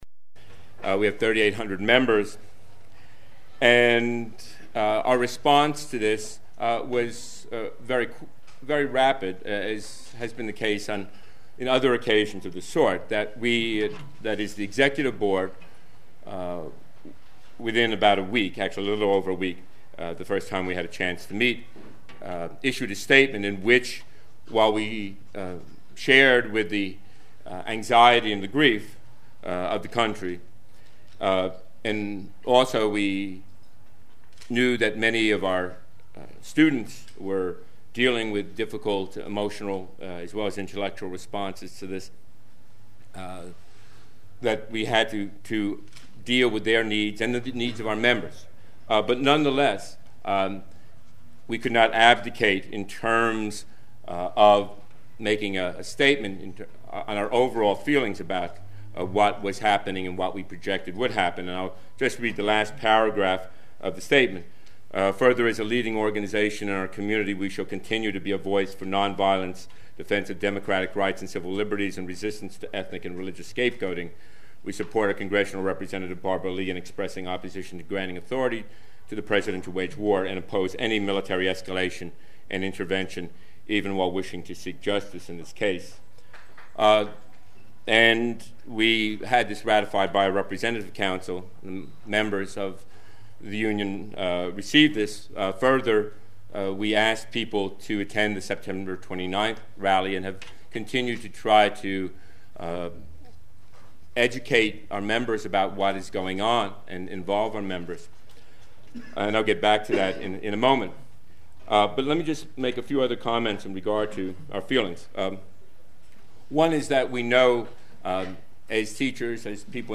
War held a forum at Laney College in Oakland on Nov. 4.